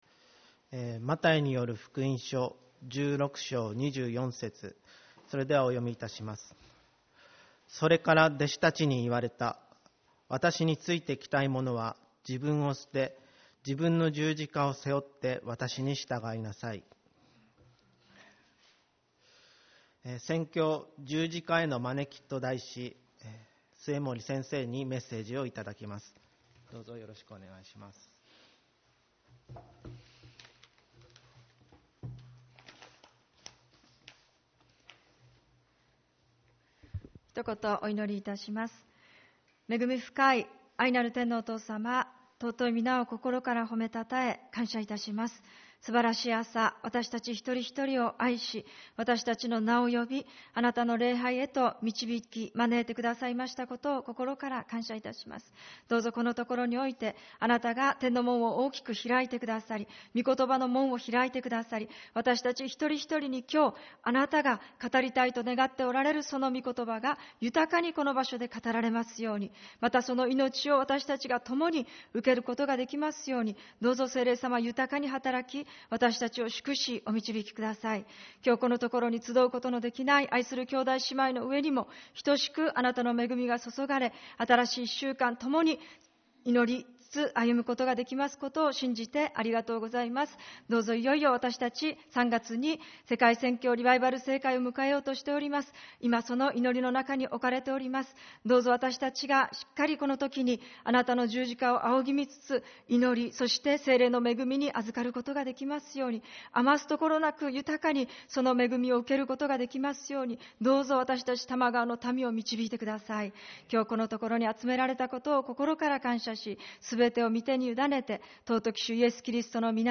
主日礼拝 「十字架への招き｣